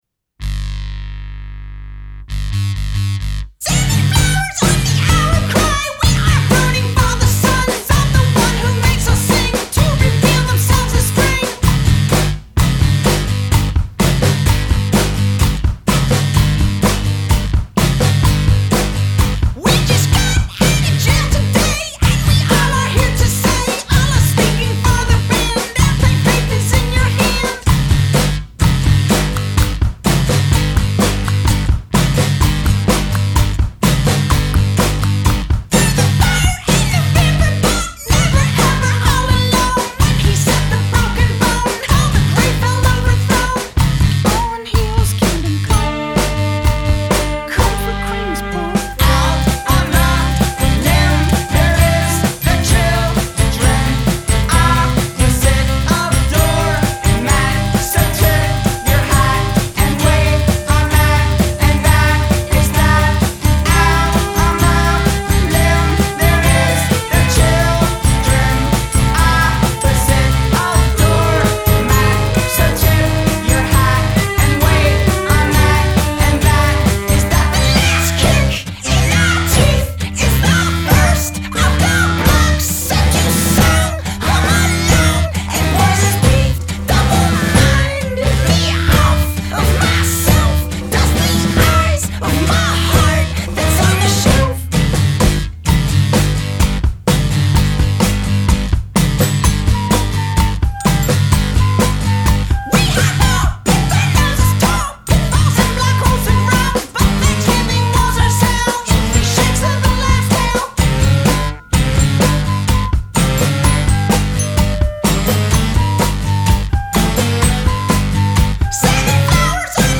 Genres: Indie pop, Acoustic rock
that plays indie pop gospel music.